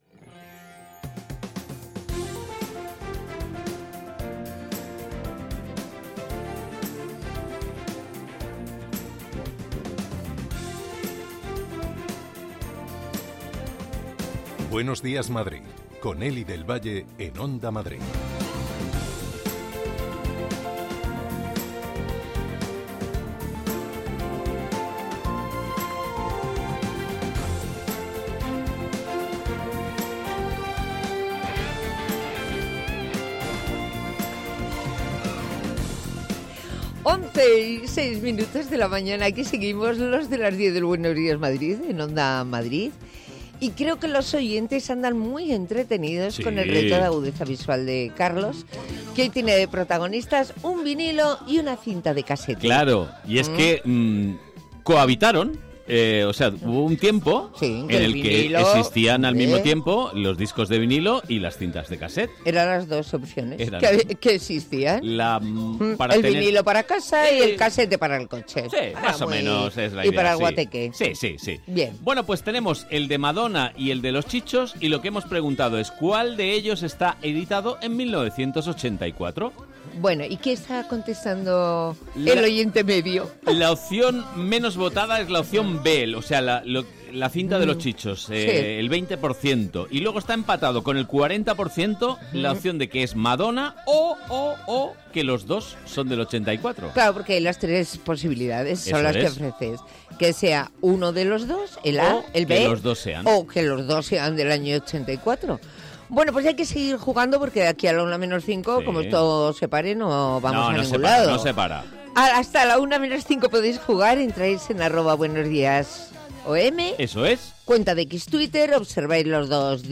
Tres horas más de radio donde se habla de psicología, ciencia, cultura, gastronomía, medio ambiente, consumo.